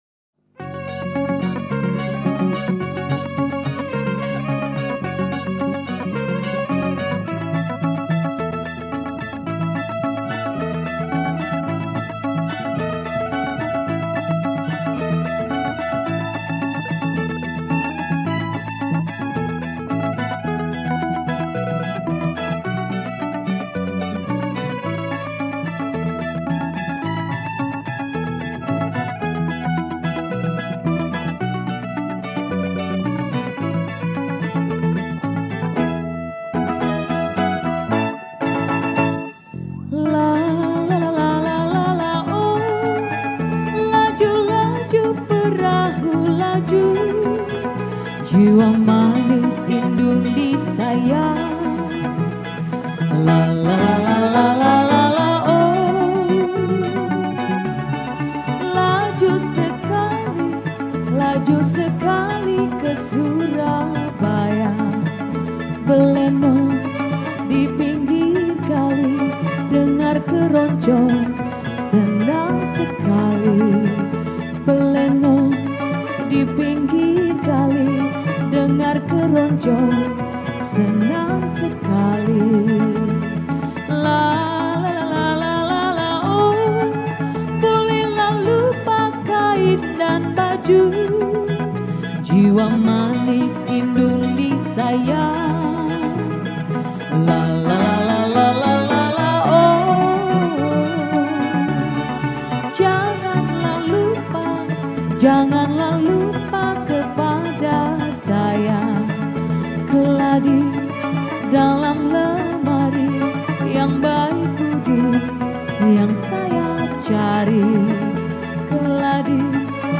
Lagu Keroncong Asli